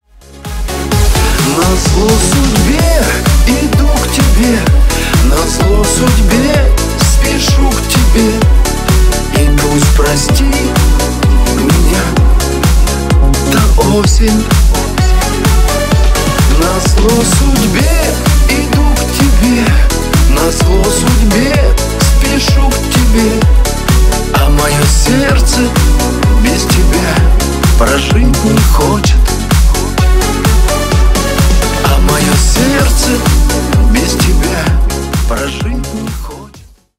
Шансон
грустные